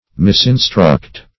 Misinstruct \Mis`in*struct"\